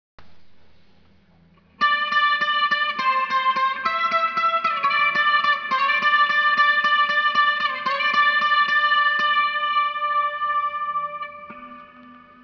Telecaster style with clear nitrocellulose and 3legs  CNC carving